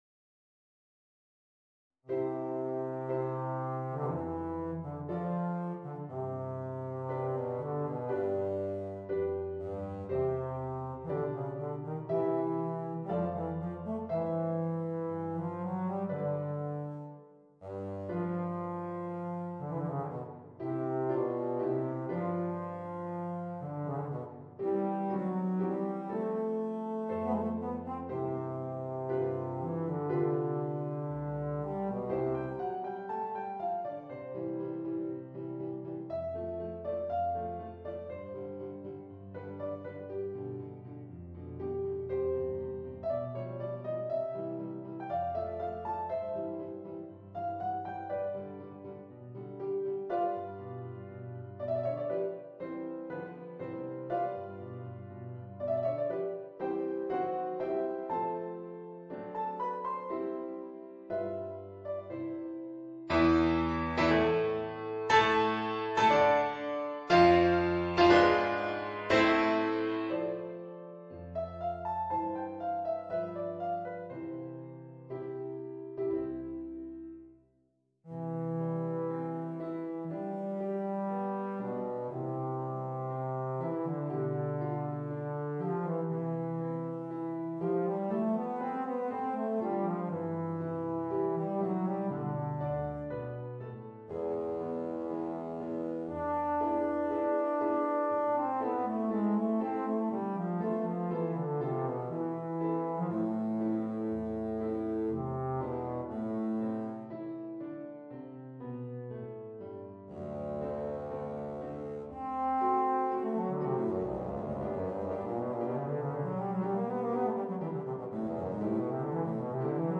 Voicing: Bass Trombone and Organ